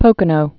(pōkə-nō)